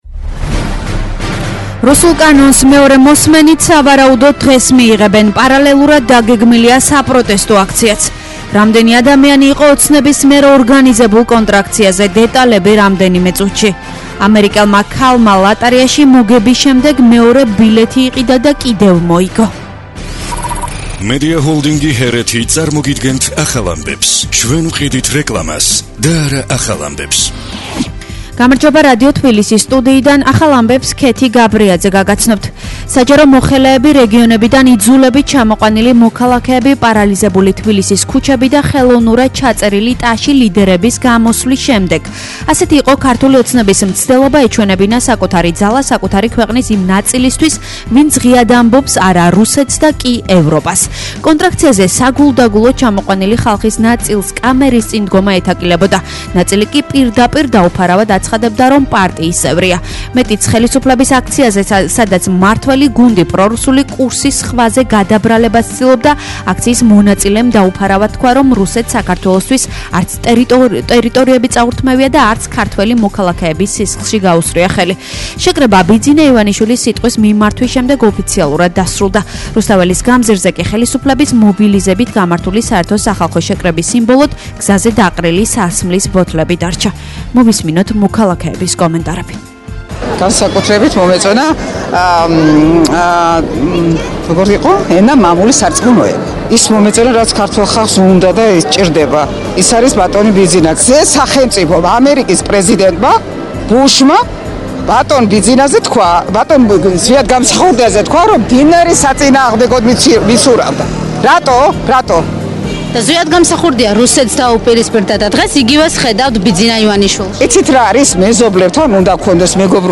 ახალი ამბები 10:00 საათზე